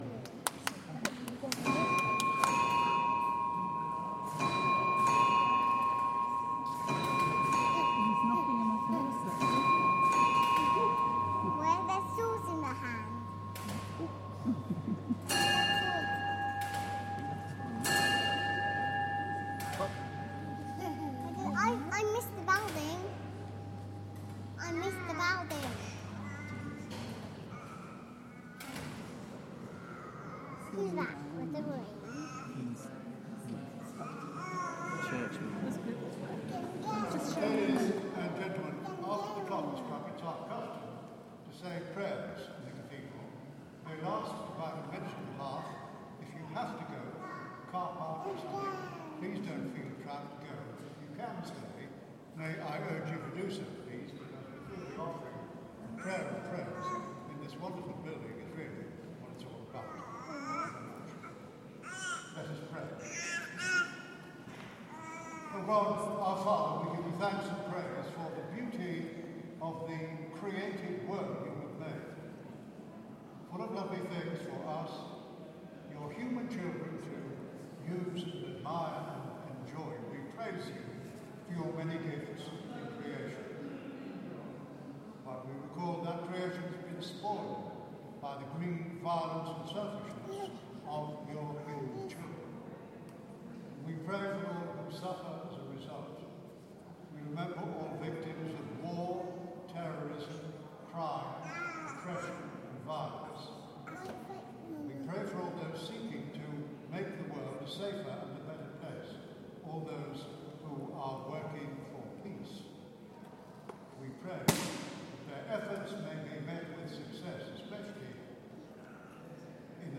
I then took a recording of a recitation from Wells Cathedral and "cut up" the track accordingly. I then used these fragments to "over-paint" a recording from a supermarket checkout queue. From this I moved to perhaps the ultimate modern incarnation of the cut-up aesthetic, Granular synthesis. The granulation of another supermarket recording is overlaid with a reading of my cut up poem.